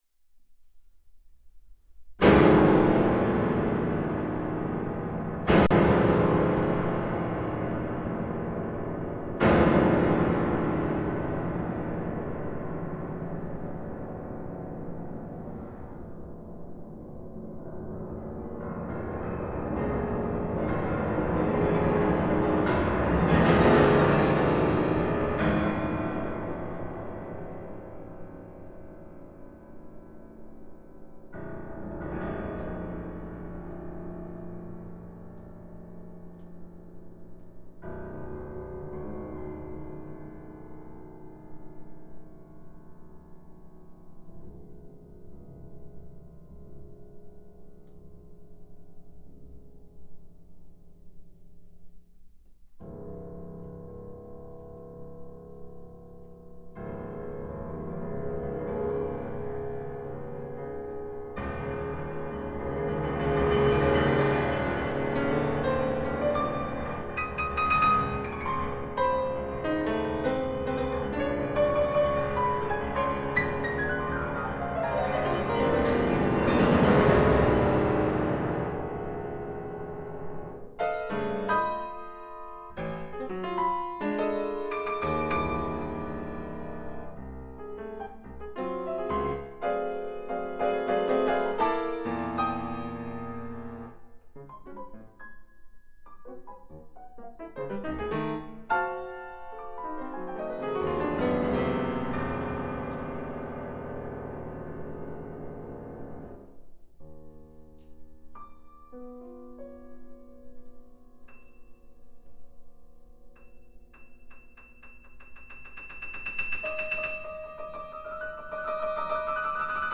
Instrumentation: Solo piano Duration